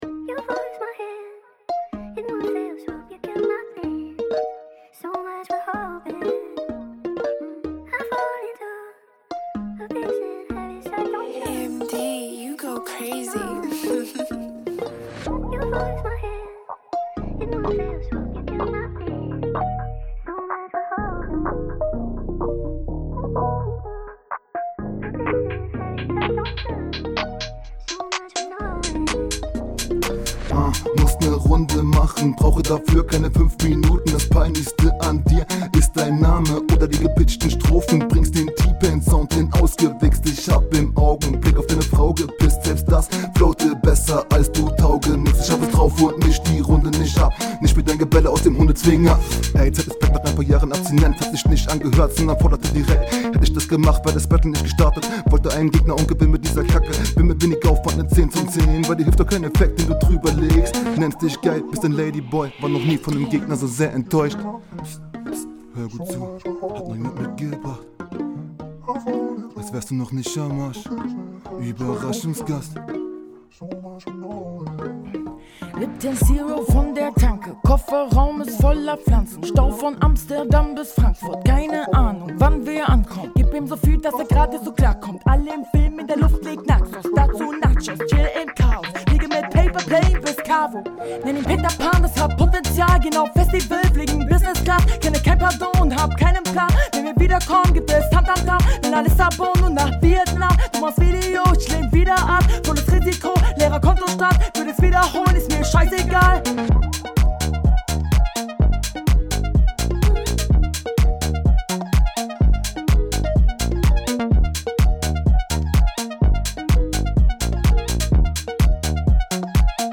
Find den Beat herbe arsch sry Ansonsten okaye Runde
Stimme etwas monoton und manchmal etwas …
Geiler Beat schon mal.
Flow ist ok, Stimme wirkt ein …